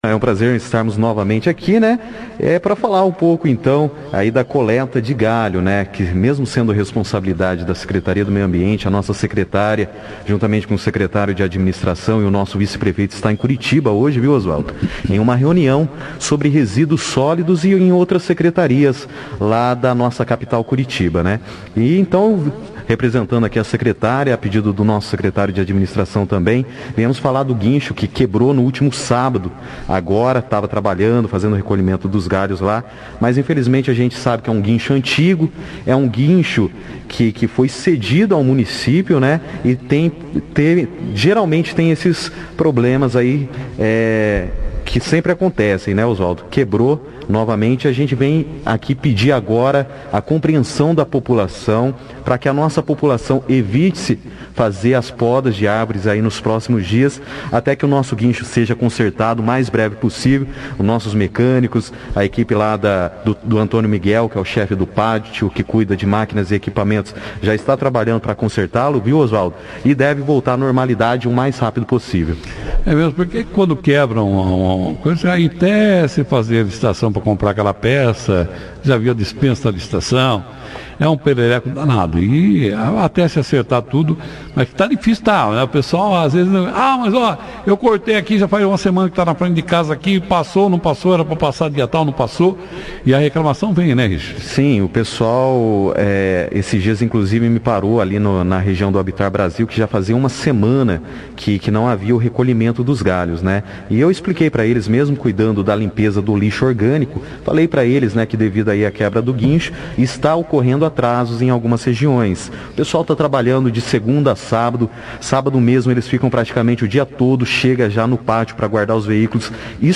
participou da 2ª edição do jornal Operação Cidade desta segunda-feira